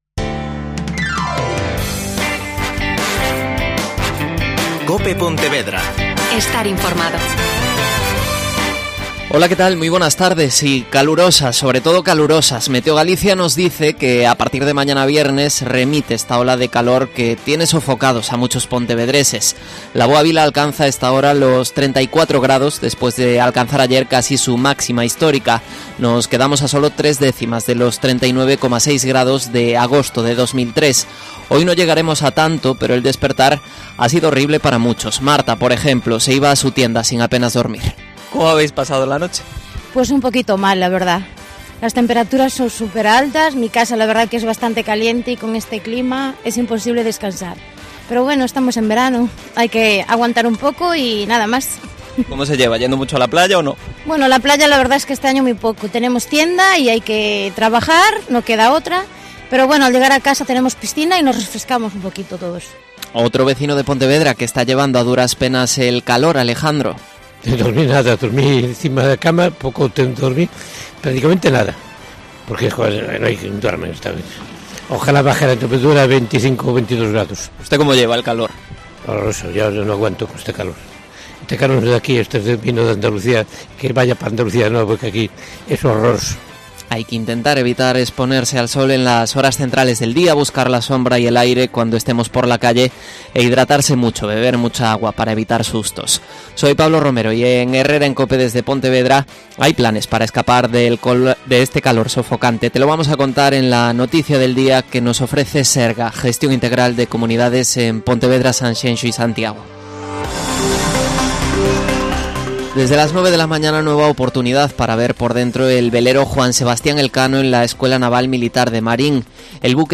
AUDIO: Noelia Ocampo. Concejala de Cultura en el Ayuntamiento de Soutomaior.